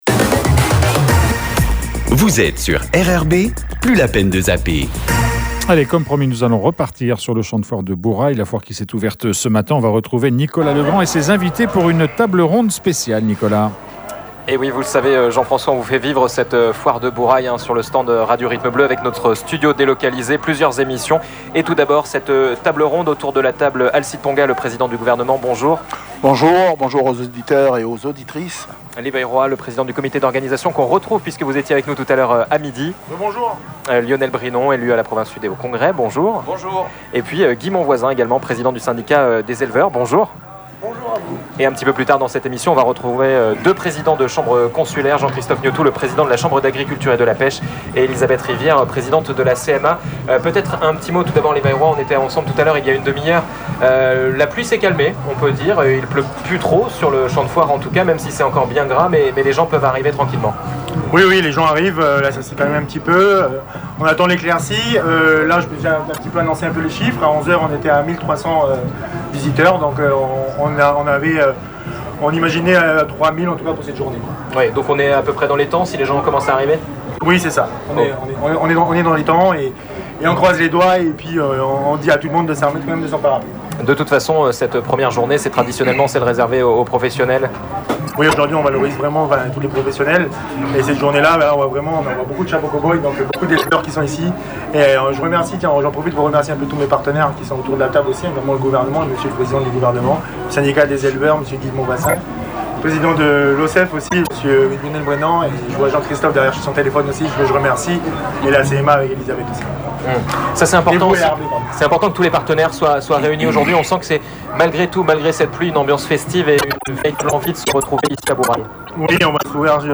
Table ronde RRB en direct de la Foire de Bourail